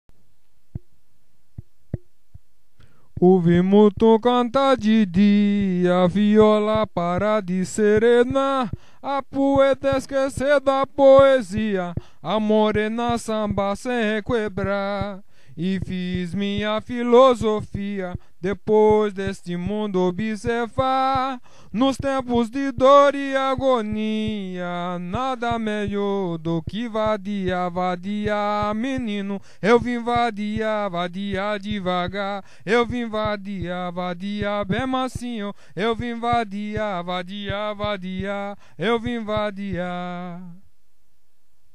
Hieronder de tekst van het lied wat is gezongen voor de Nieuwjaarsroda: